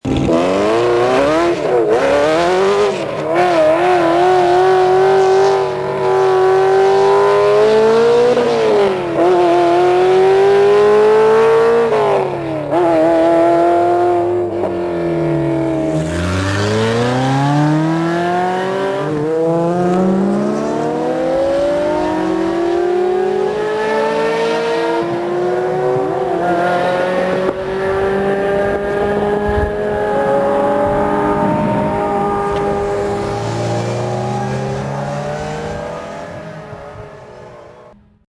348 355 ch F50 (380KB MP3 Format) Here is a quick clip of a Ferrari 348 followed by an F355 Challenge racer, then an F50... new